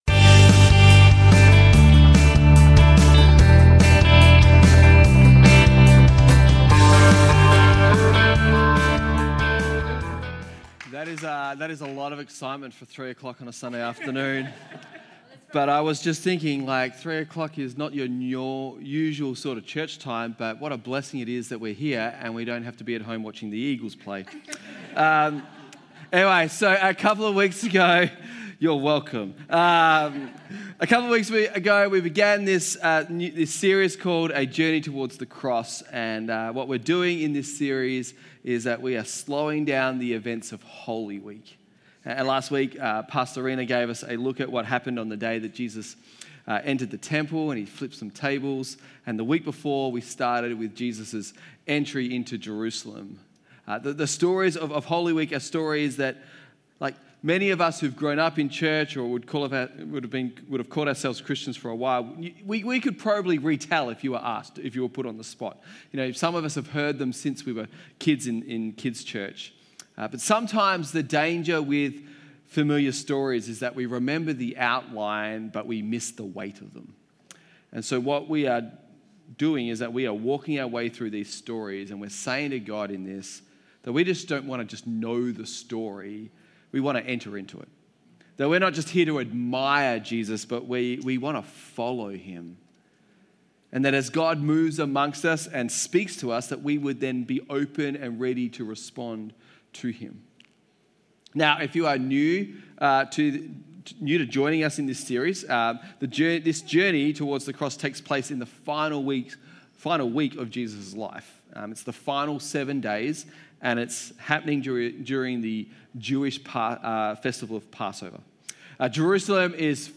Sermons | Firstlight Church